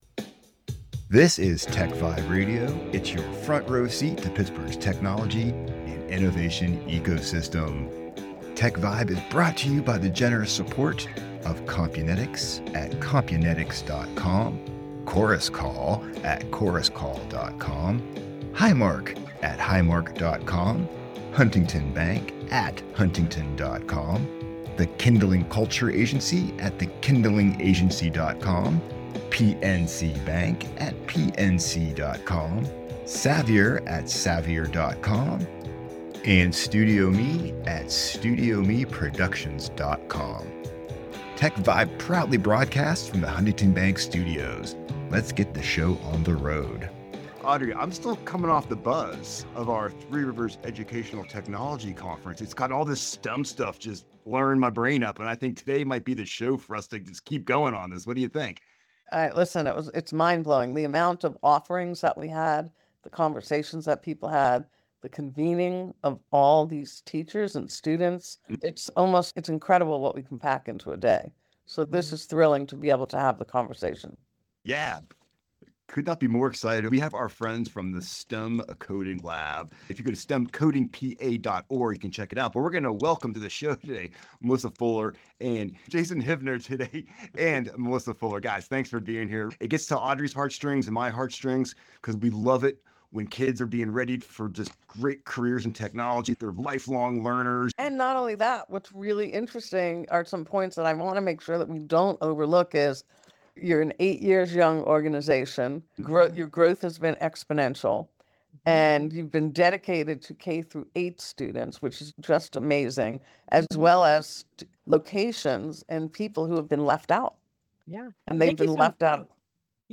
Don't miss this conversation packed with insights, passion, and ideas to shape a tech-ready generation.